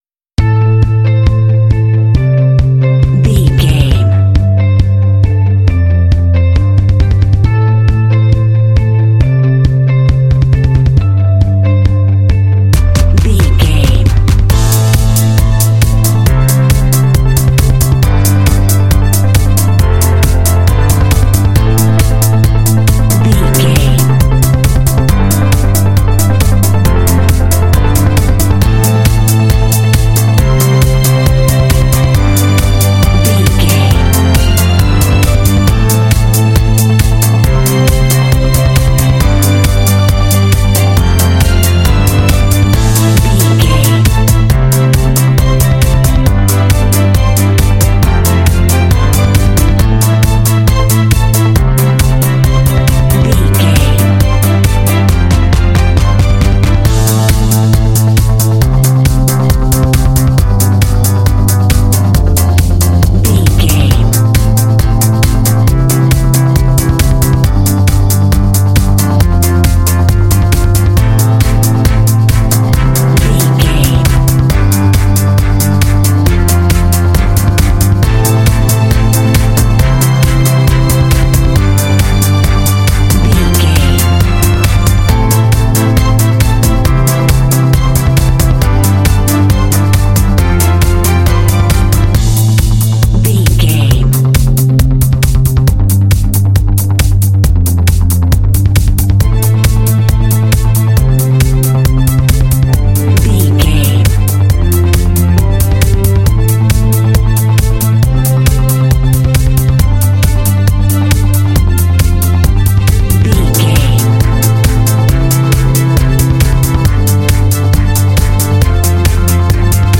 Epic / Action
Fast paced
Aeolian/Minor
driving
energetic
bass guitar
drums
synthesiser
electric guitar
strings
synth pop
alternative rock
indie